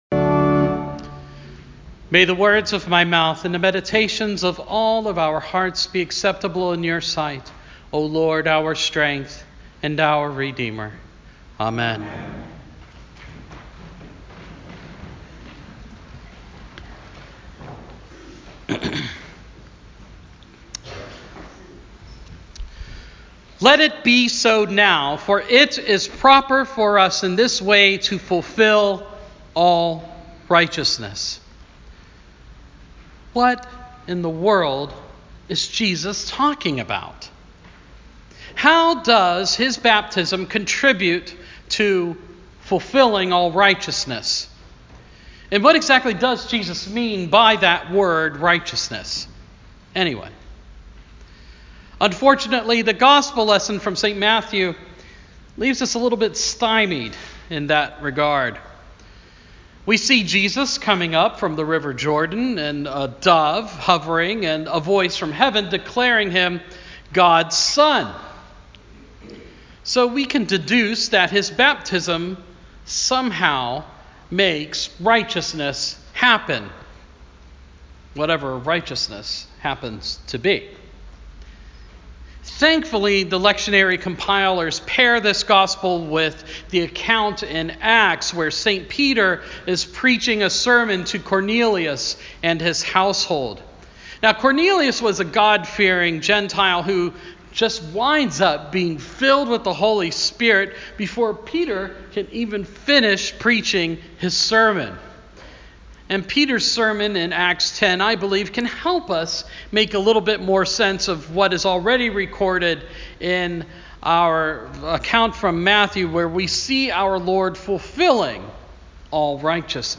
Sermon – Baptism of Our Lord